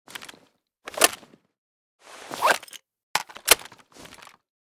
wa2000_reload.ogg